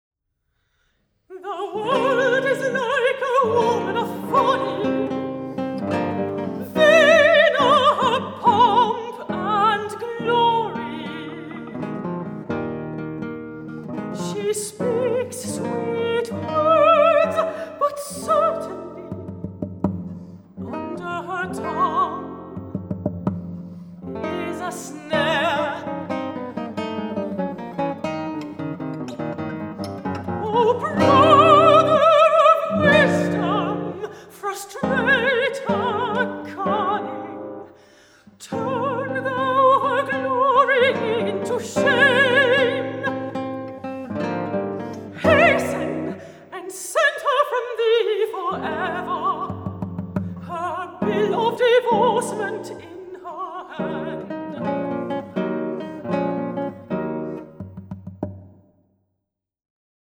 guitar
mezzo-soprano